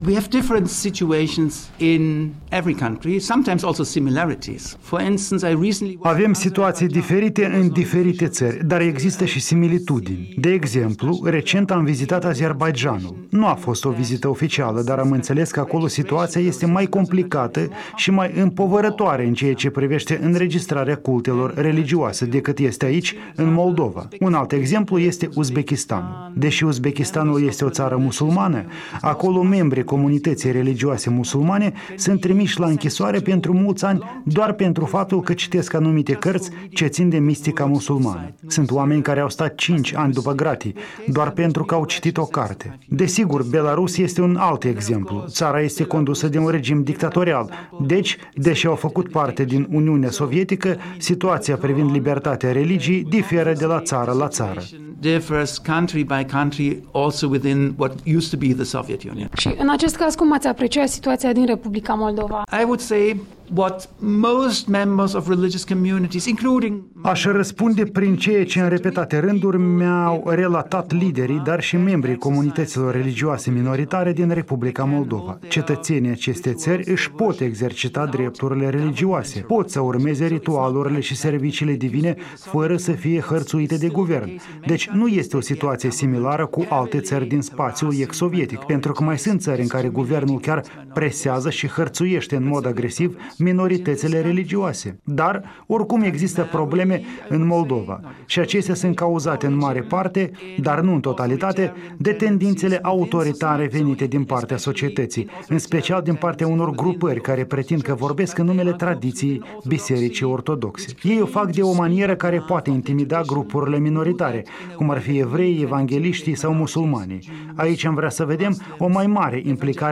Un interviu cu raportorul ONU pentru libertate religioasă, Heiner Bielefeldt